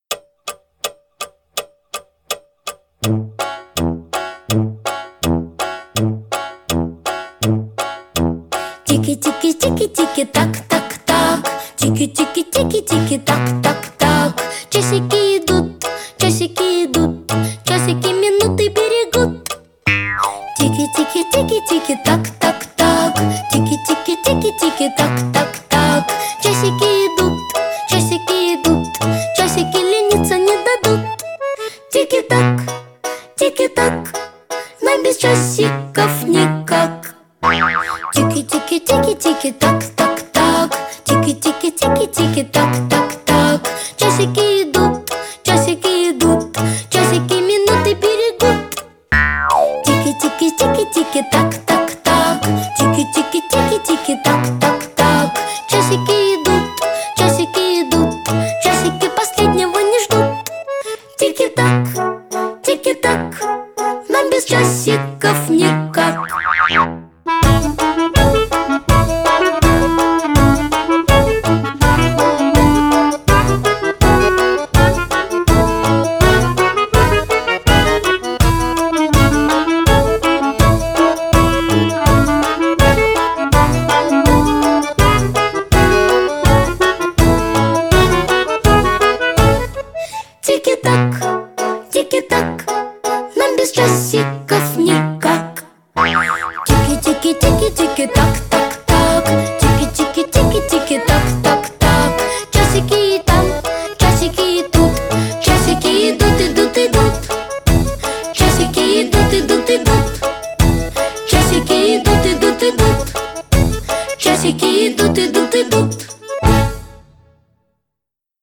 современные песни для детей